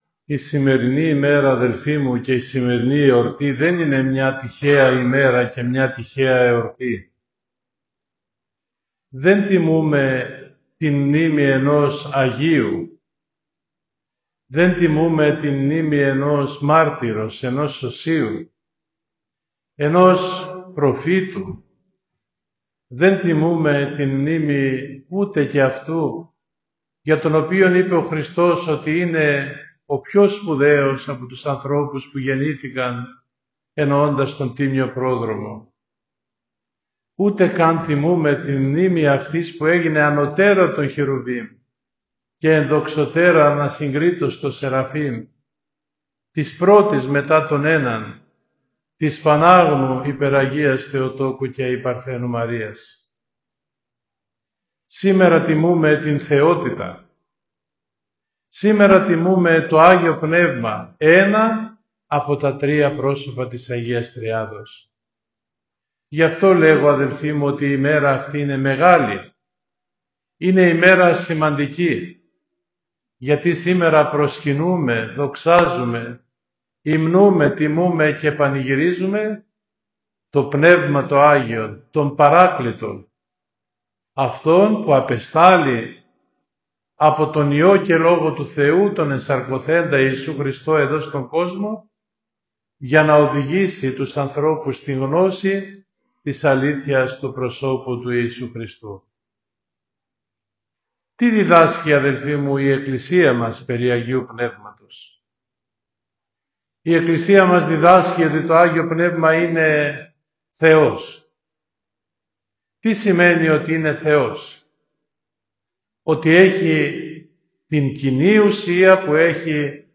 Με την παρουσία χιλιάδων πιστών εορτάστηκε σήμερα, Δευτέρα 5 Ιουνίου 2023, η μεγάλη εορτή του Αγίου Πνεύματος στον πανηγυρίζοντα Ιερό Ναό της Αγίας Τριάδας, στην ομώνυμη περιοχή της Ακτής Θερμαϊκού, της Ιερά Μητροπόλεως Νέας Κρήνης και Καλαμαριάς.
Τον Θείο Λόγο κήρυξε ο Μητροπολίτης Νέας Κρήνης και Καλαμαριάς κ. Ιουστίνος τον οποίο μπορείτε να ακούσετε στο ακόλουθο ηχητικό αρχείο: